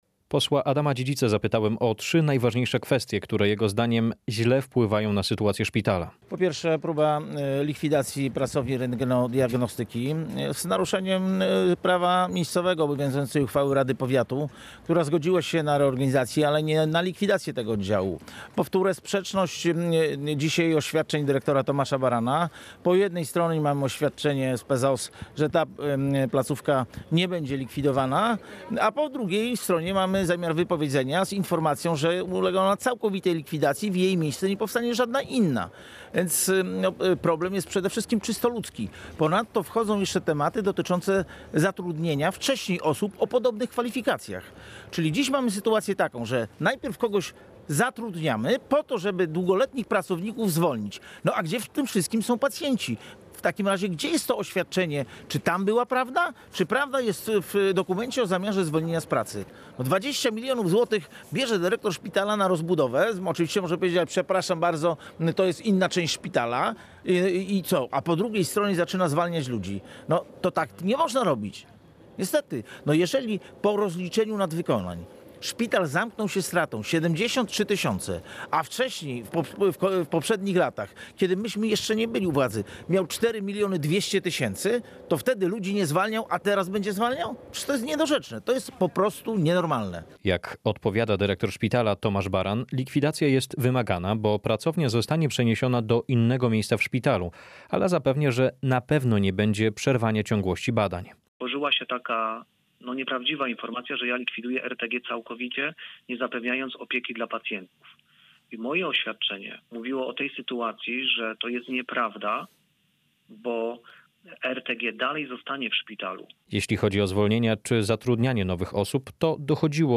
Relacje reporterskie • Decyzje dotyczące pracowni rentgenodiagnostyki oraz zwolnień pracowników wywołały rozbieżności. Pojawiają się pytania o zgodność działań z prawem, sytuację personelu i bezpieczeństwo pacjentów.